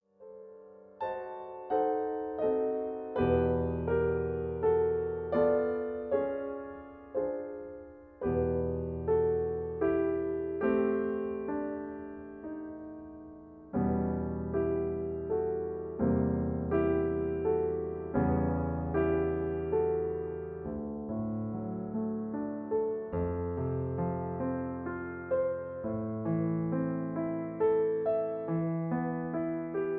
In F-sharp. Piano Accompaniment